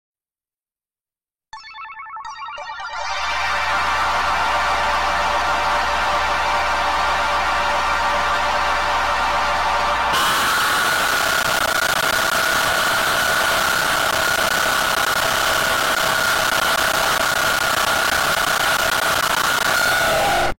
Only 10 Second The Bouncing Sound Effects Free Download